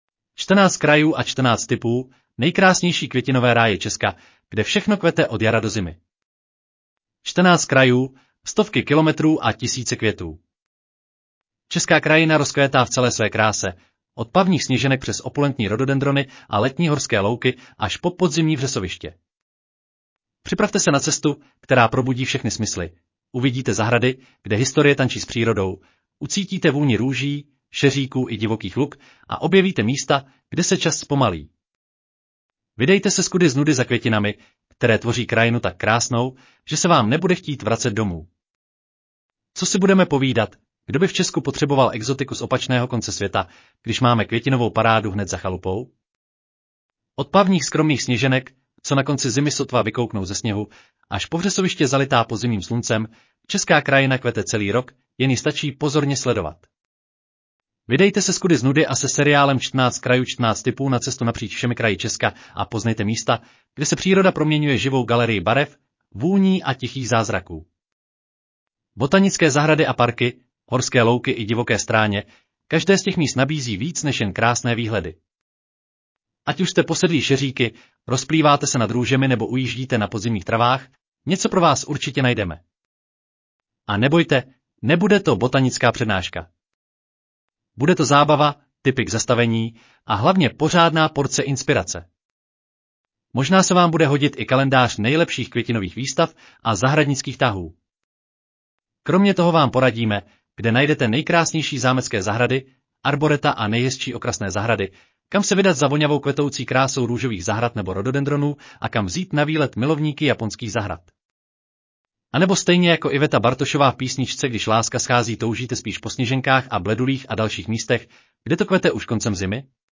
Audio verze článku Čtrnáct krajů & čtrnáct tipů: nejkrásnější květinové ráje Česka, kde všechno kvete od jara do zimy